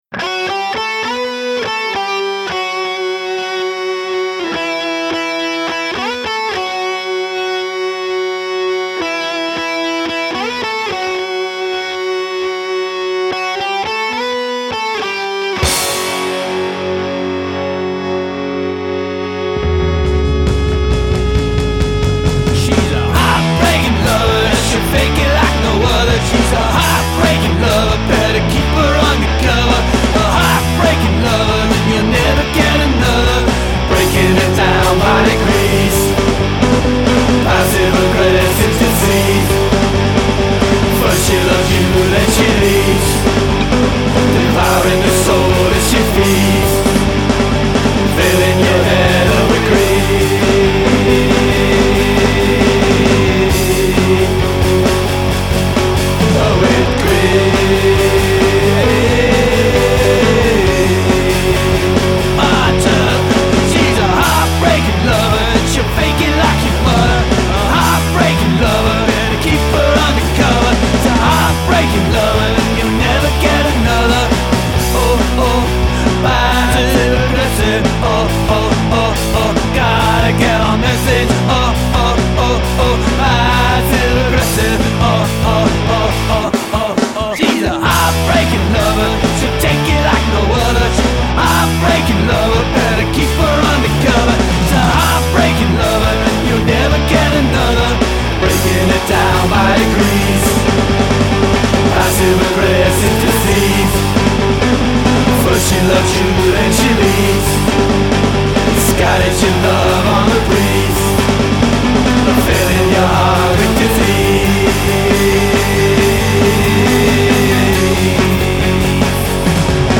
The drumming is really good on this track.